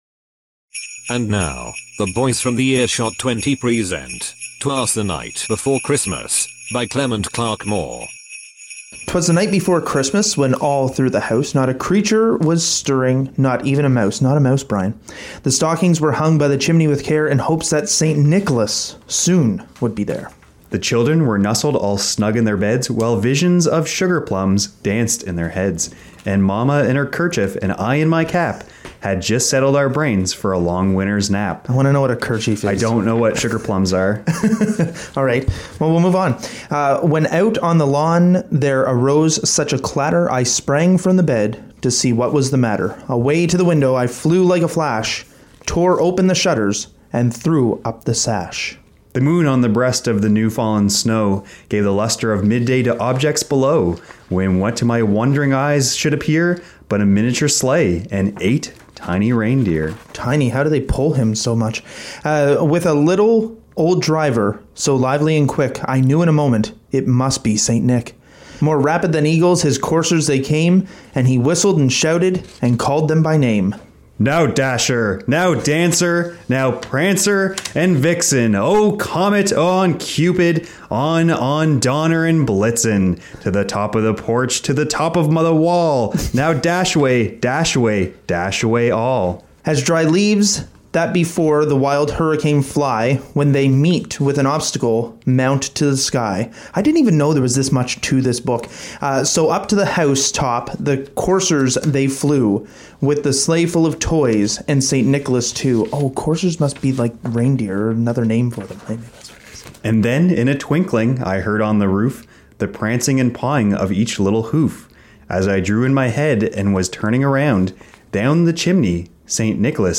!earshot 20 Presents A Reading Of Twas The Night Before Christmas
Recording Location: Local 107.3FM (CFMH) - Saint John, New Brunswick
Type: Speech/Presentation
0kbps Stereo